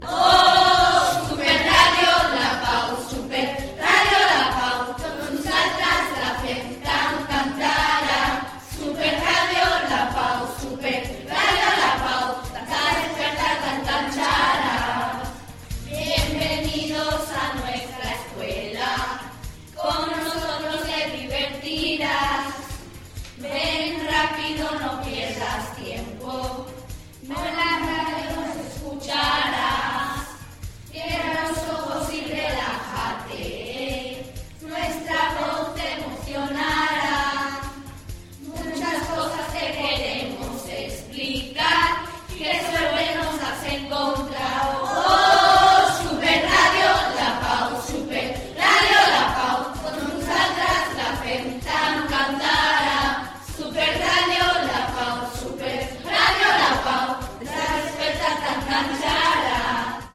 Sintonia cantada de l'emissora